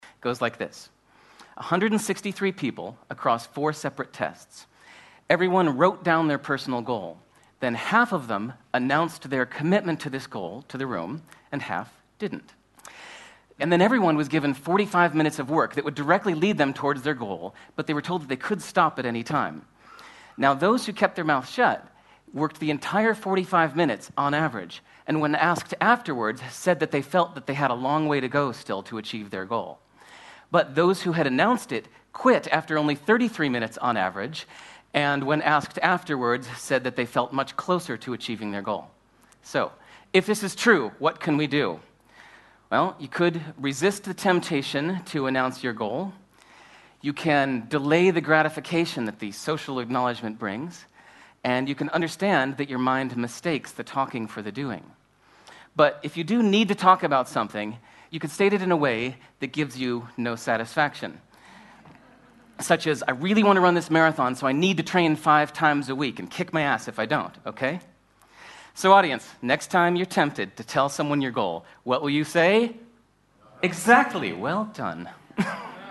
TED演讲:不要公开宣布个人目标(2) 听力文件下载—在线英语听力室